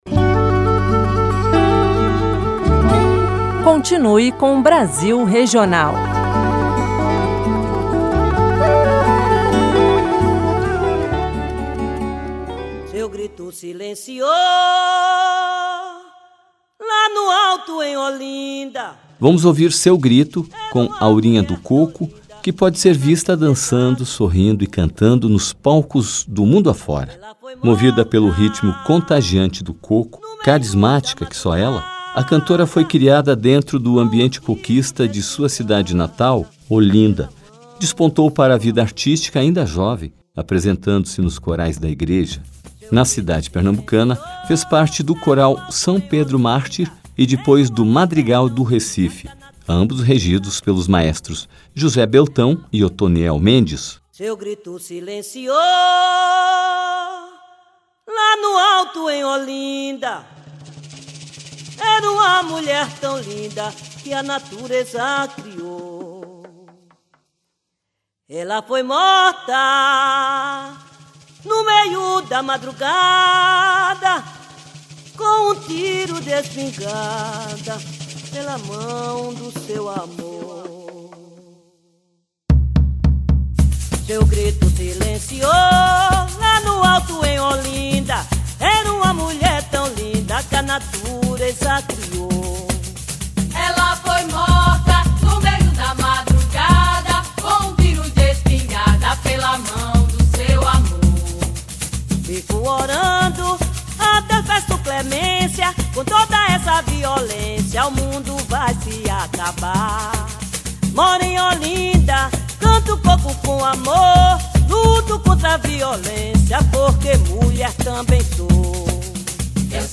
cavaquinho
transita por vários gêneros musicais